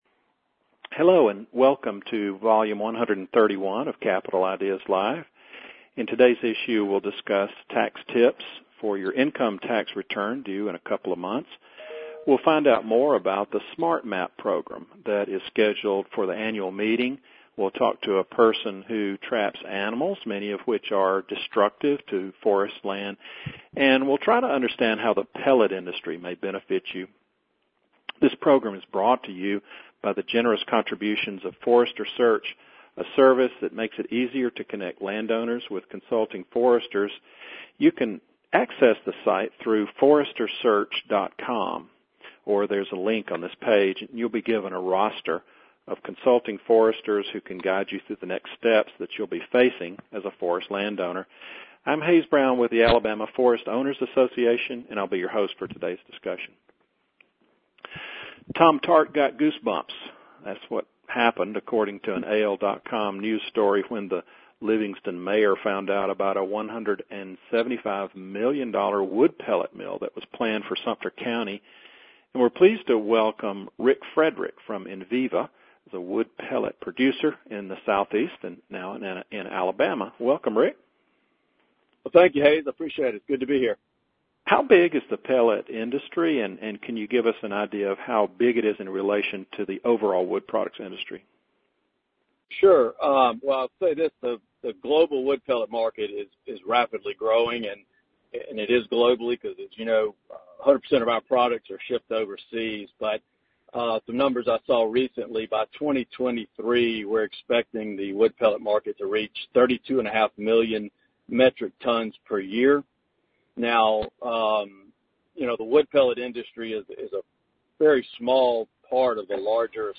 FEBRUARY 2020 News Conference for Forest Owners Produced by the Alabama Forest Owners' Association, Inc. This Conference was recorded at 10:00 AM Central Time on February 12, 2020 with a live audience.